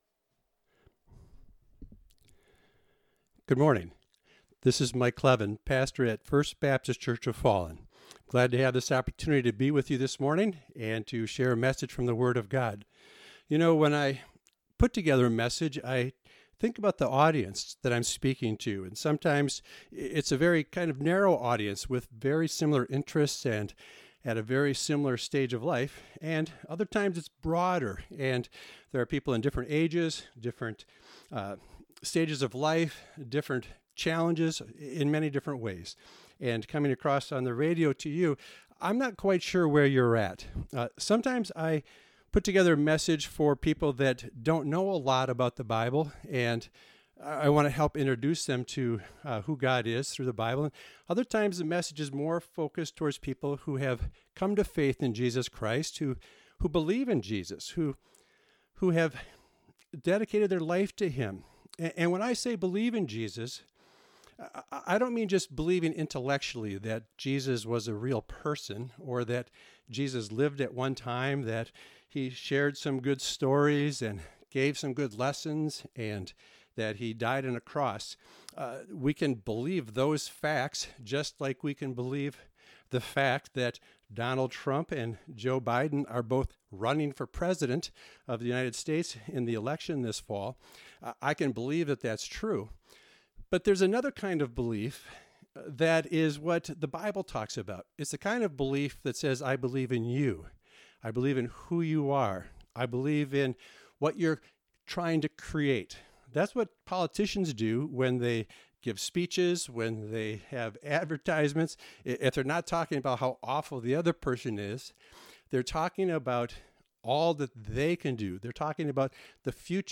#7 • Ephesians 1:17-23; 6:10-18; Downloads & Resources • MP4 Video File • MP3 Audio File The MP3 audio file is the radio version of the message recorded for broadcast on WILLIE 105.7 AM, Siren, Wisconsin.